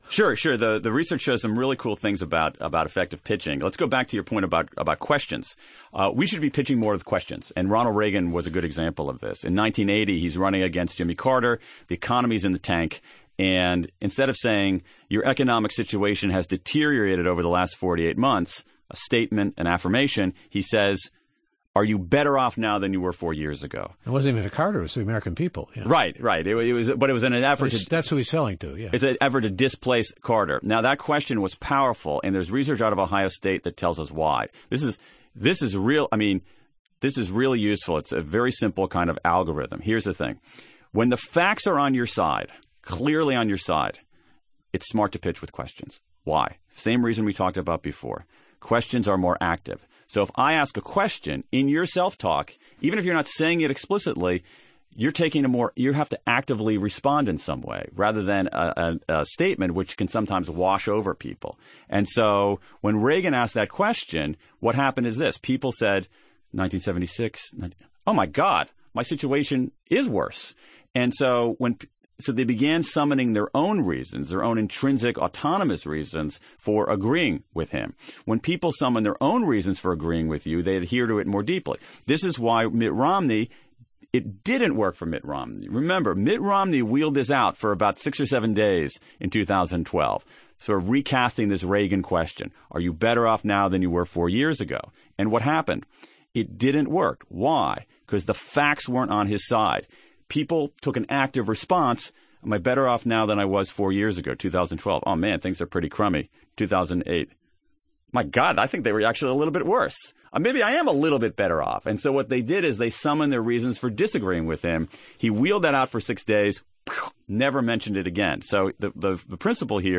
Today on NPR’s Forum, author Daniel Pink talked about his new book, To Sell Is Human: The Surprising truth about moving others. Pink says it’s okay to ask a question when the facts are on your side.